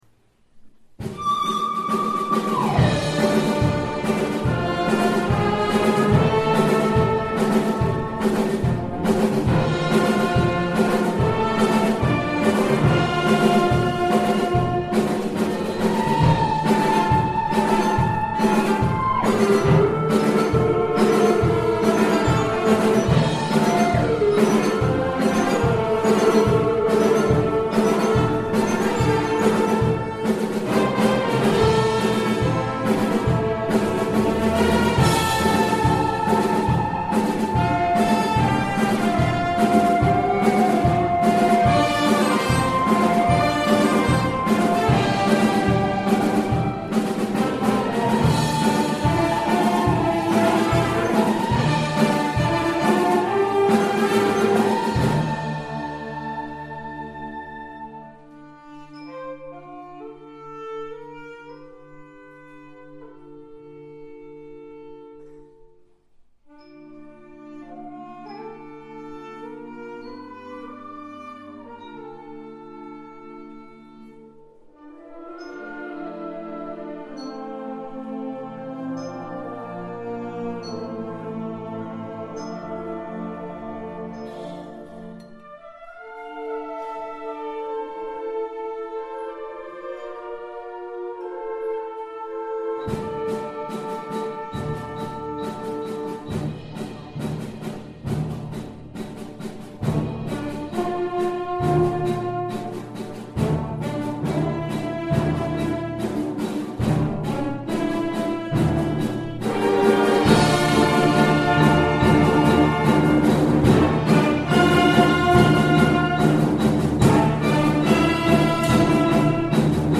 Concert Band.